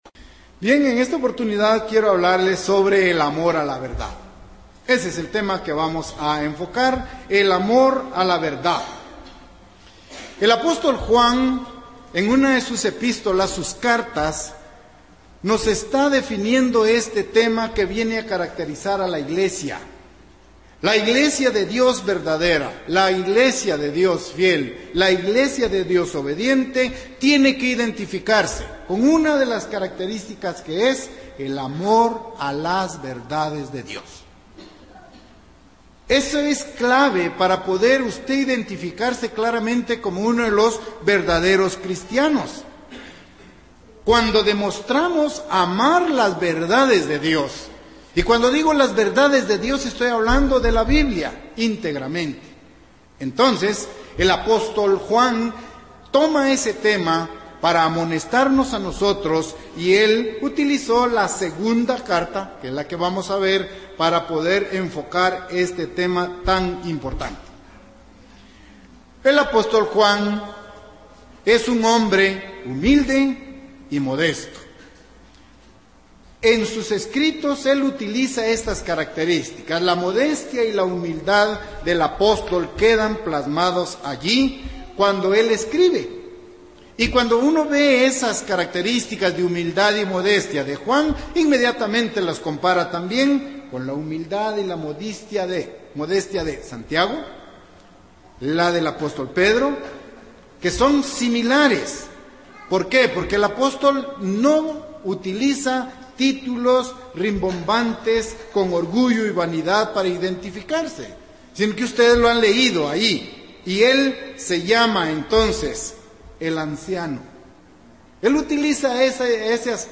Después de conocer la verdad ¿cómo puedo llegar a tener amor a la verdad? Este sermón describe las acciones y actitudes para poder alcanzarlo.